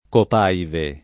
[ kop # ive ]